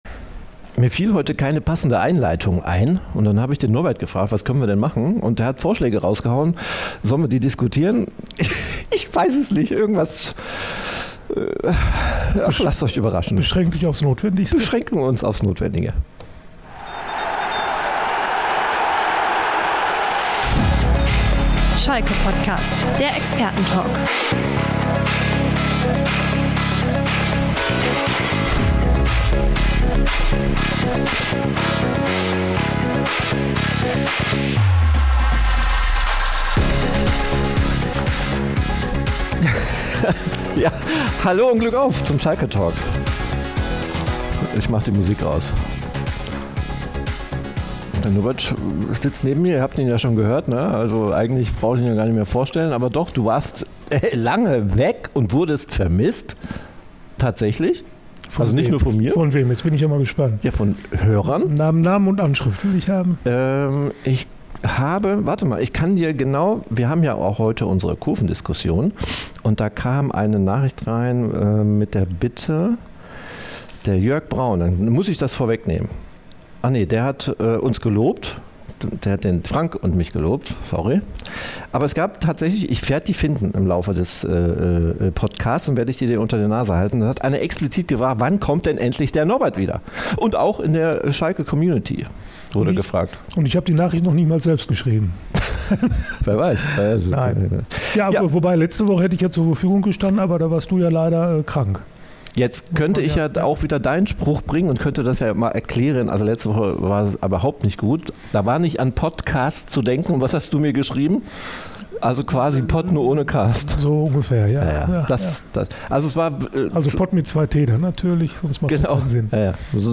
Kurve und Klima: Viele Strohfeuer, aber kein Flächenbrand - Der „Schalke-POTTcast“ – Episode 206 ~ Schalke POTTcast - Der Experten-Talk zu S04 Podcast